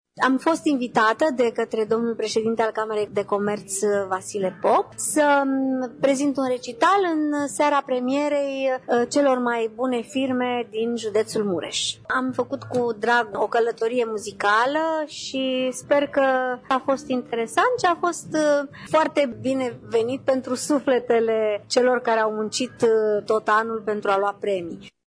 Sub genericul „Împreună pentru afacerea ta”, la Tîrgu Mureș s-a desfășurat, joi seară, gala „Topul firmelor din judeţul Mureş”.
Pe lângă premii și diplome, organizatorii au oferit performerilor economiei mureșene și un recital extraordinar
soprana